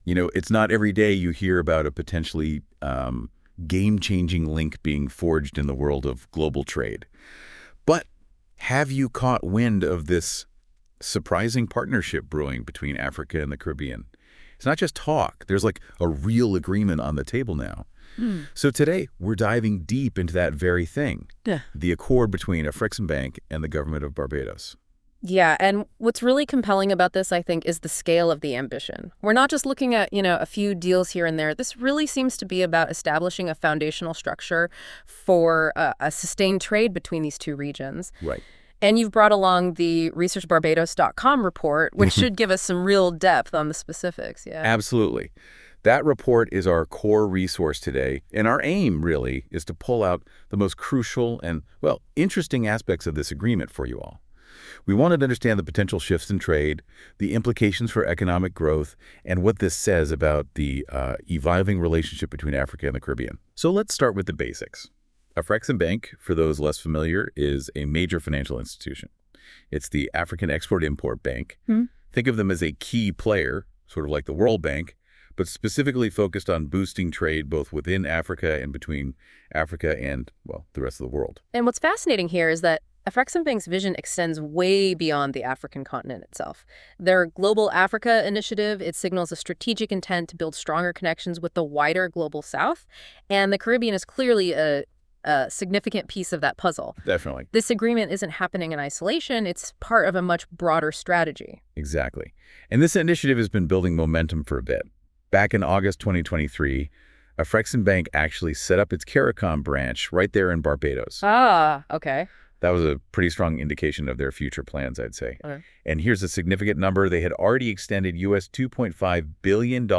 Exclusive expert commentary on the landmark trade agreement